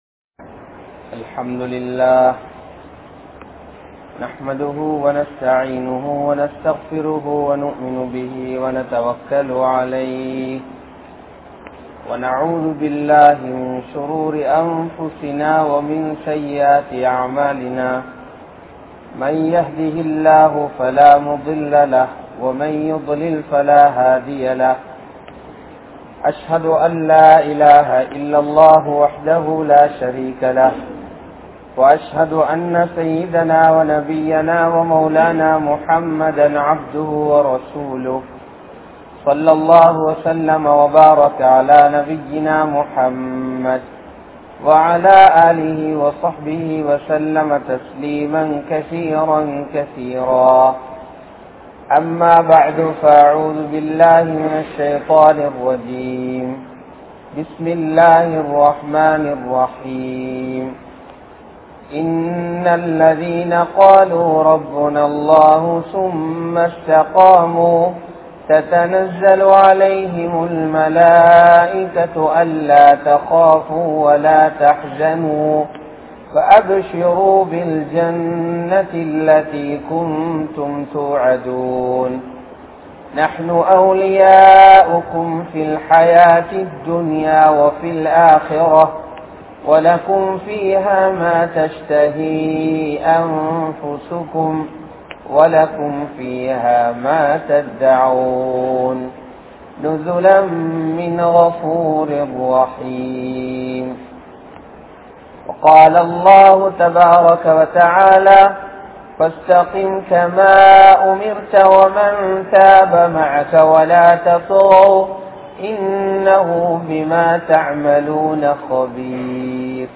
Nabi Valiyai Pin Pattrugal | Audio Bayans | All Ceylon Muslim Youth Community | Addalaichenai